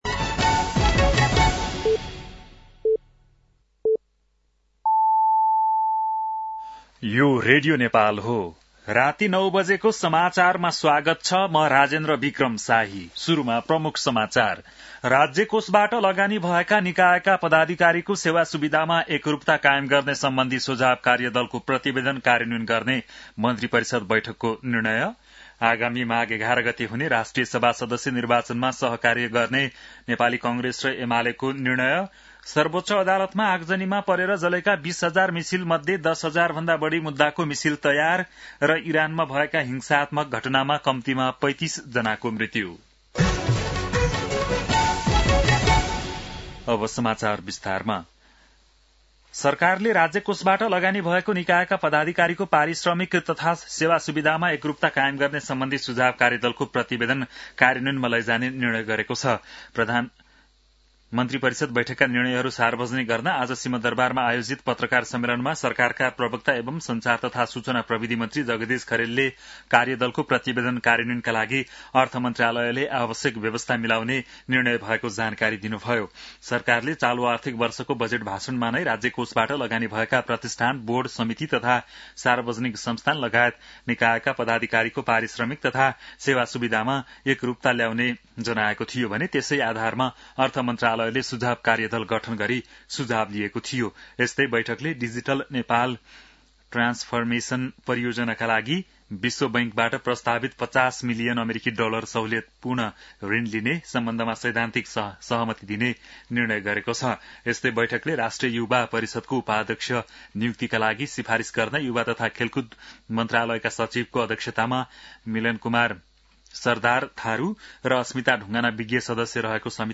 An online outlet of Nepal's national radio broadcaster
बेलुकी ९ बजेको नेपाली समाचार : २२ पुष , २०८२